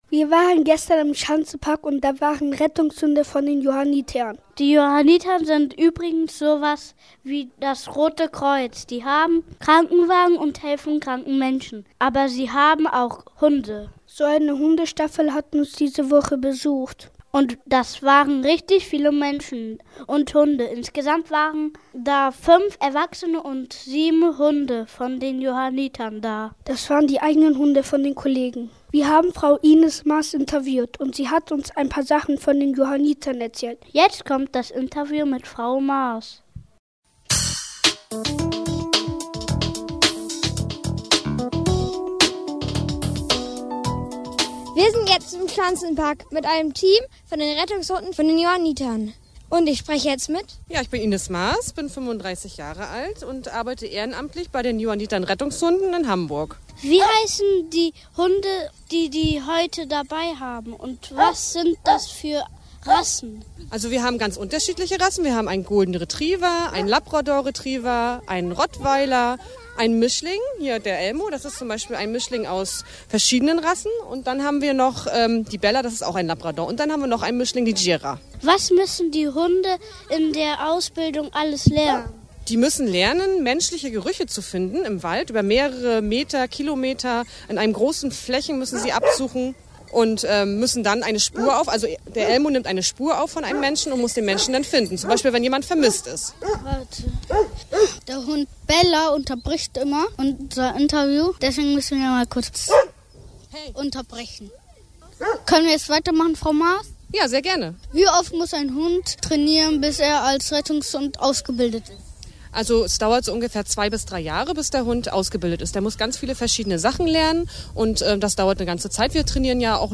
reportage-rettungshunde.mp3